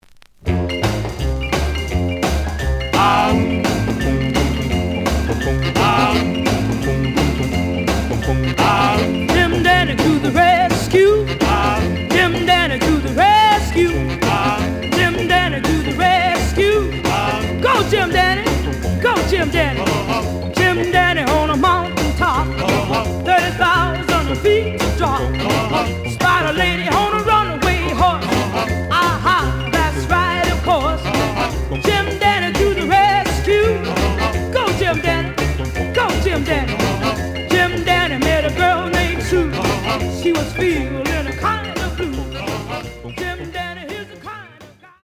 The audio sample is recorded from the actual item.
●Genre: Rhythm And Blues / Rock 'n' Roll
Some click noise on later half of B side due to scratches.